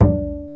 Index of /90_sSampleCDs/Roland - String Master Series/STR_Cb Pizzicato/STR_Cb Pizz 2